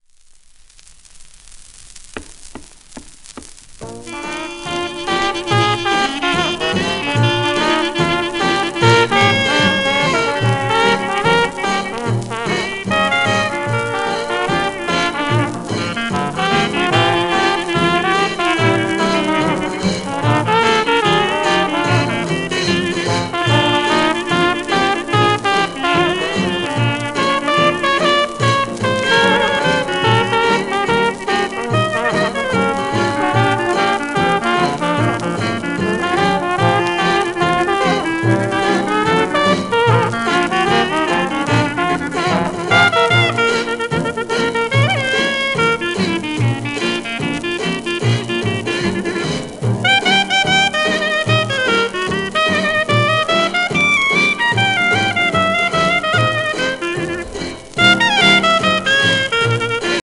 1951年録音
1950年代から半世紀以上現役で活躍した英国のジャズ・トランペッター
ロンドンのRoyal Festival Hallでのライブ録音